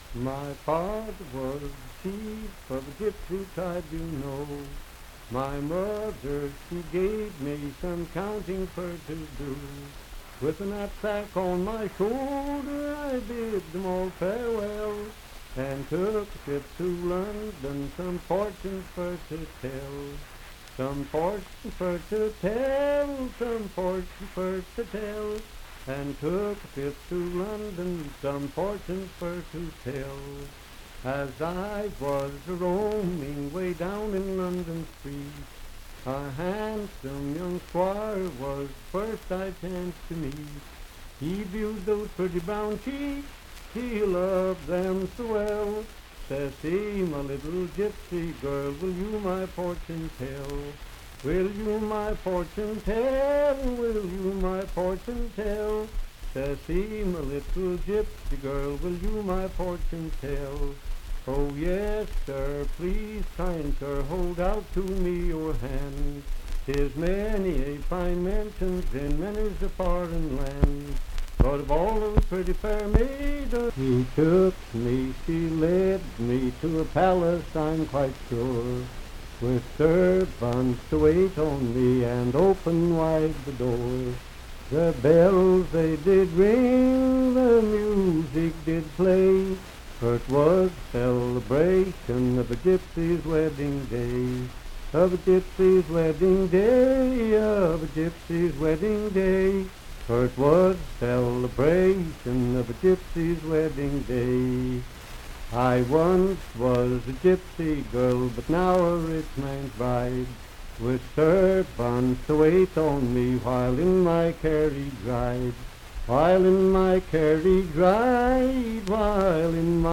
Unaccompanied vocal music
Disk 40.-41 Verse-refrain 3d(6).
Voice (sung)
Franklin (Pendleton County, W. Va.), Pendleton County (W. Va.)